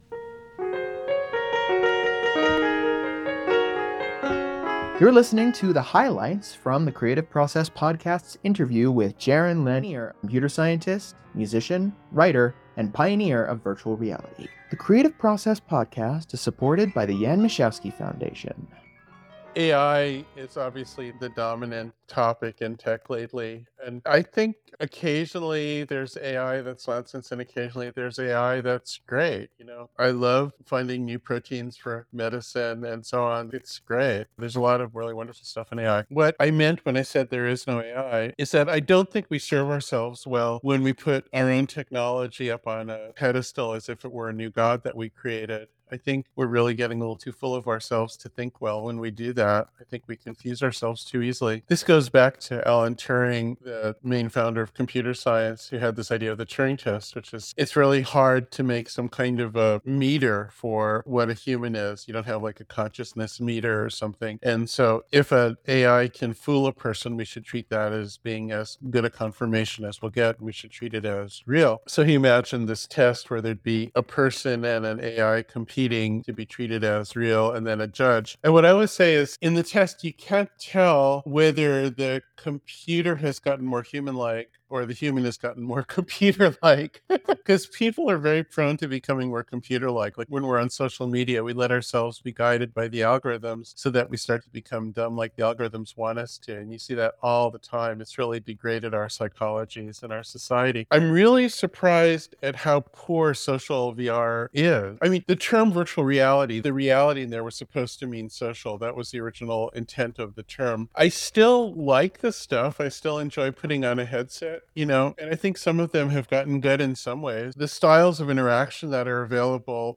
Conversations with writers, artists & creative thinkers across the Arts & STEM. We discuss their life, work & artistic practice.
JARON-LANIER-the-creative-process-podcast-HIGHLIGHTS-NEW.mp3